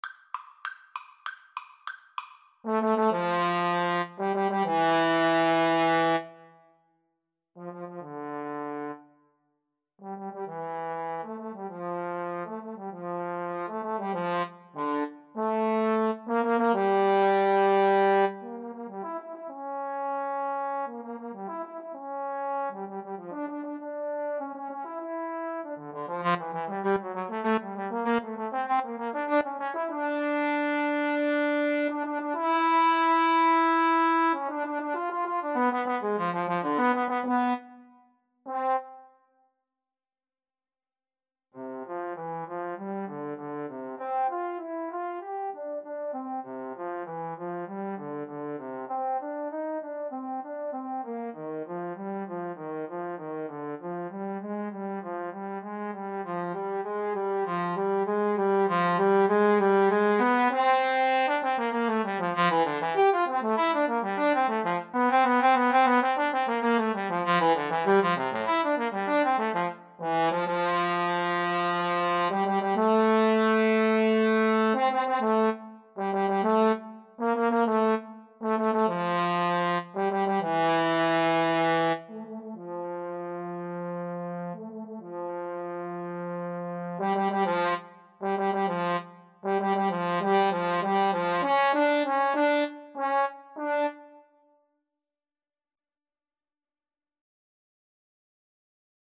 Allegro con brio (=108) =98 (View more music marked Allegro)
2/4 (View more 2/4 Music)
Classical (View more Classical Trombone Duet Music)